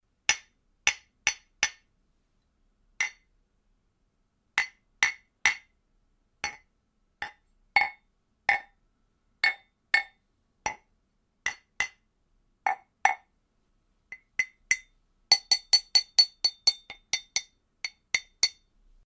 mug hits - glass ear candy.mp3
Recorded with a Steinberg Sterling Audio ST66 Tube, in a small apartment studio.
mug_hits_-_glass_ear_candy_mhl.ogg